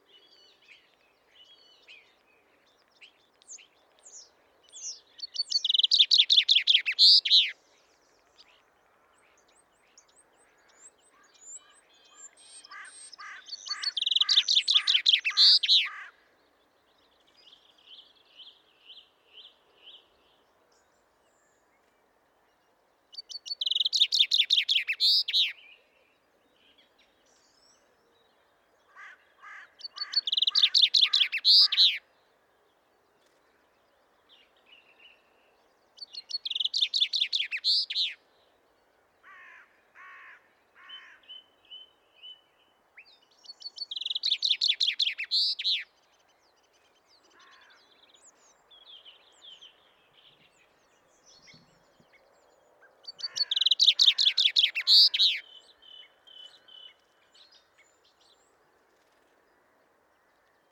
معرفی سهره جنگلی
نام انگلیسی :Chaffinch
نام علمی :Fringilla coelebs